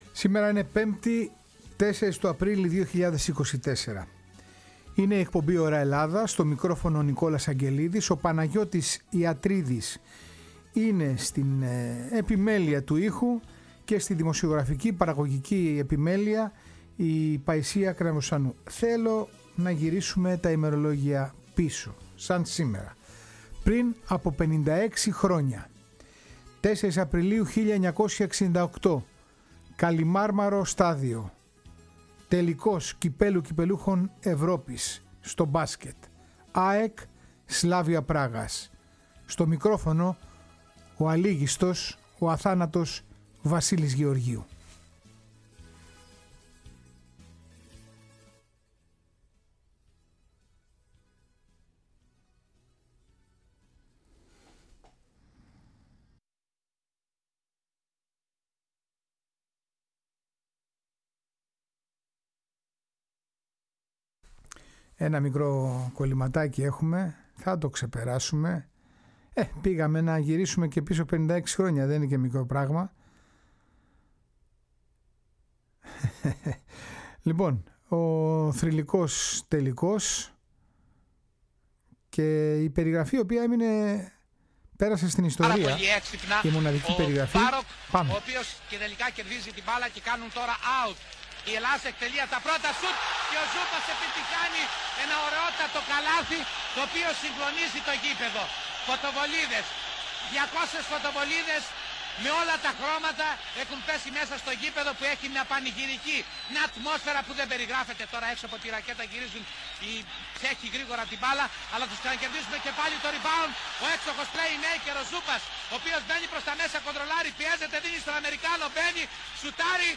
τραγούδια που αναφέρονται στον άθλο της Ένωσης και σπάνια αποσπάσματα που δεν έχουν ξανακουστεί στο ραδιόφωνο!